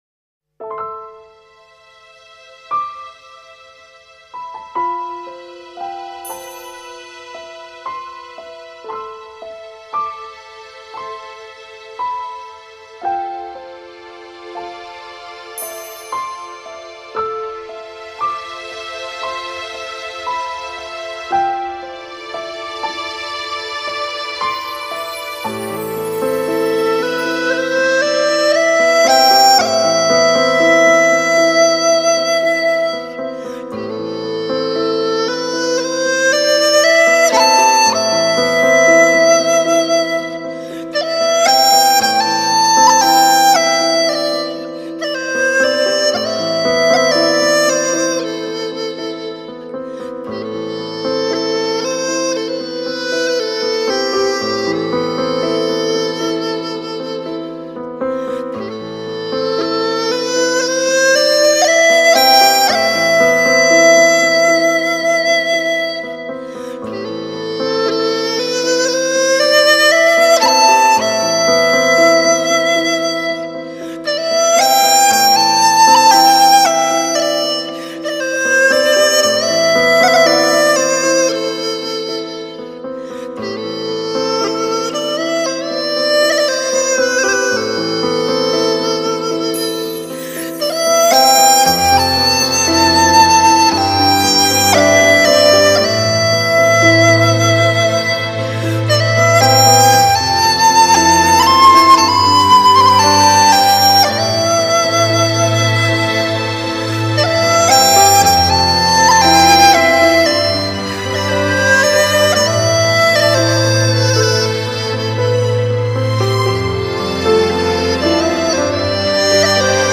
融合世界音乐与新世纪音乐风格，打造中国首张“笛、箫”跨界发烧唱片，HD直刻无损高音质音源技术，HIFI限量珍藏版！